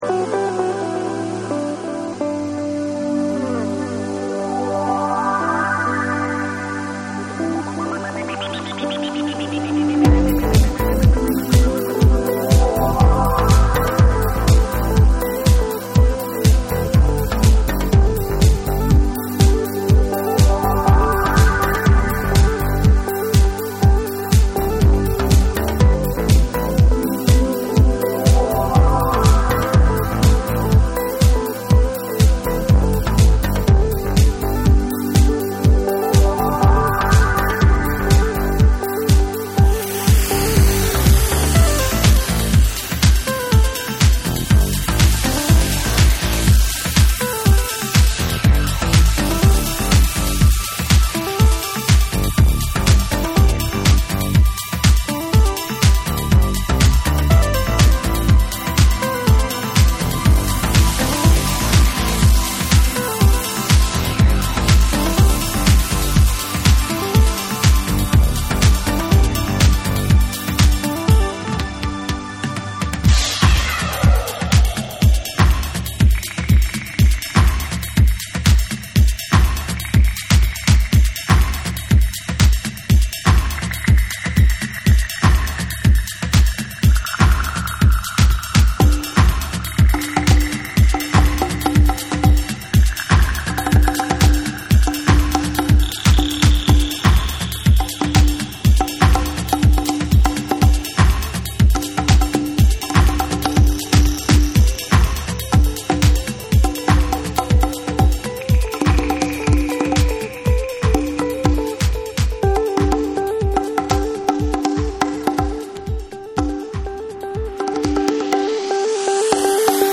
爽快で多幸感のある流麗なメロディーが空間的に舞うバレアリック・ナンバーを披露する
グルーヴィーに唸るベースラインがリードを取る
TECHNO & HOUSE